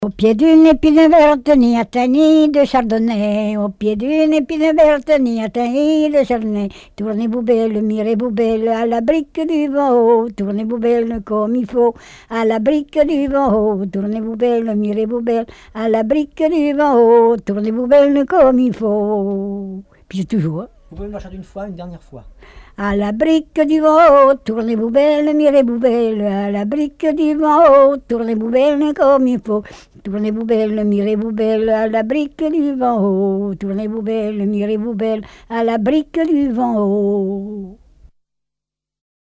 danse : branle
collecte en Vendée
Répertoire de chants brefs et traditionnels
Pièce musicale inédite